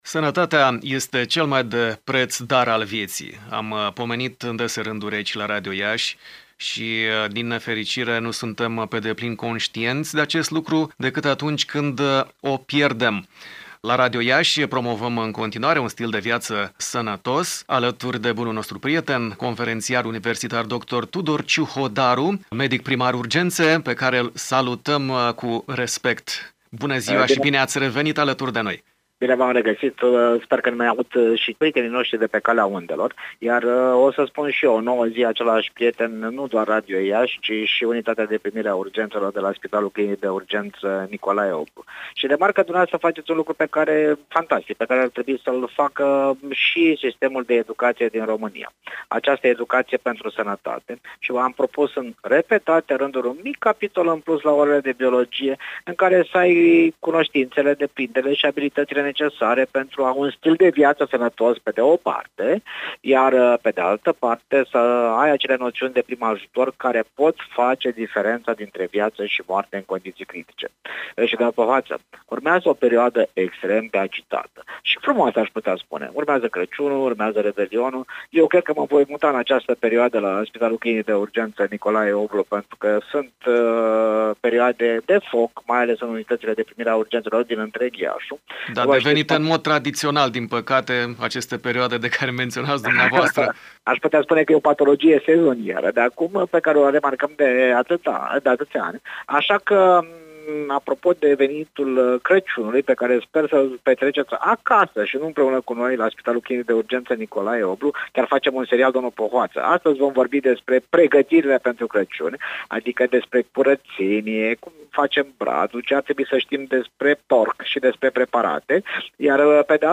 Sursă: conf. univ. dr. Tudor Ciuhodaru, medic primar urgențe.